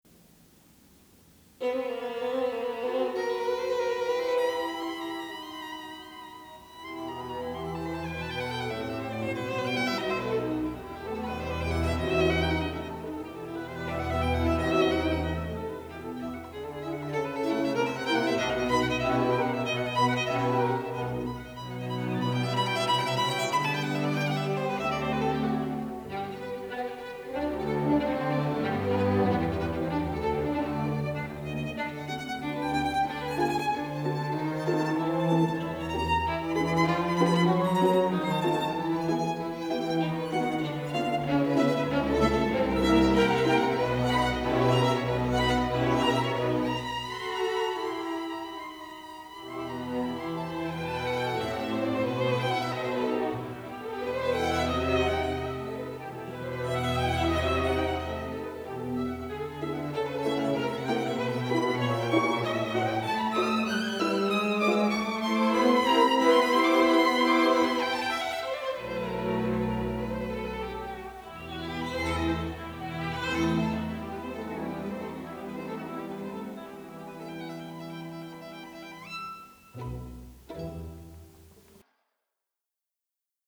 mp3/chamber_orchestra/NOV-07